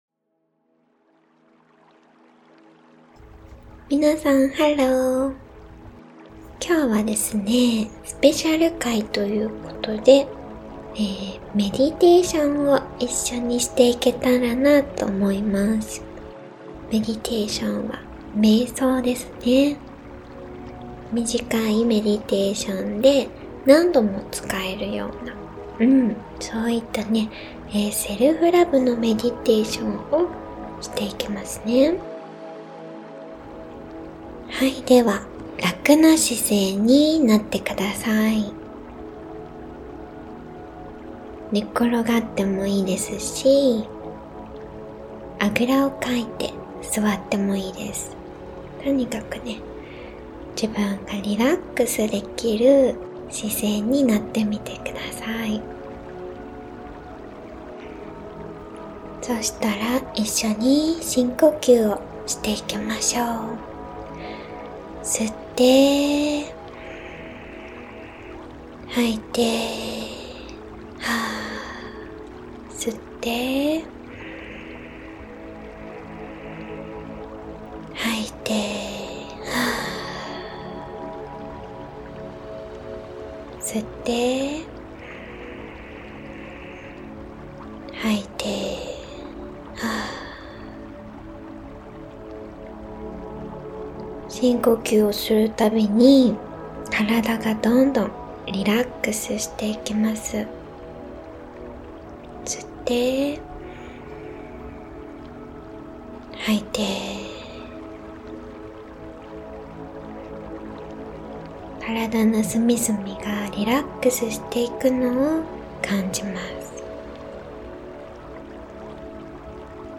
【５分で心が整う】セルフラブメディテーション(誘導瞑想) - sielu |シエル
きらきら輝くエネルギーを自分の中に入れて、ネガティブな気持ちを自分の外へデトックス。いつでもどこでもイメージするだけで元気が出て、心が整うメディテーション(誘導瞑想)です。